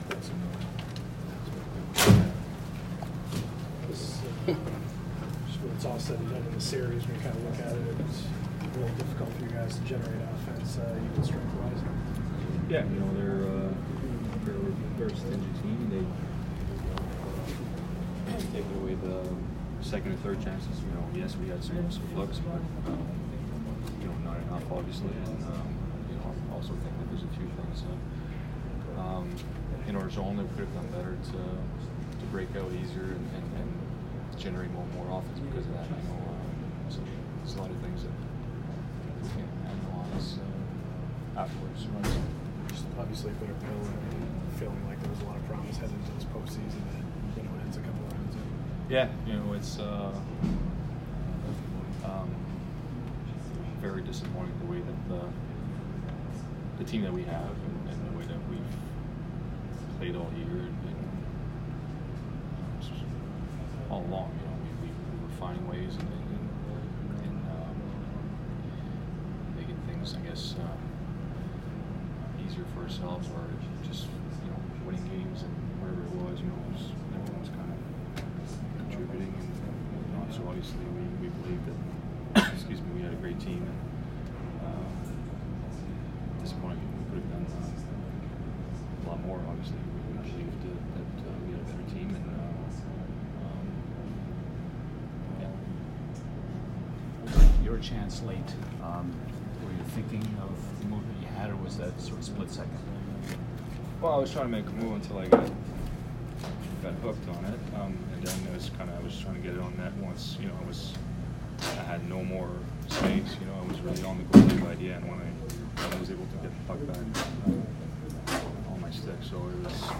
Patrice Bergeron post-game 5/6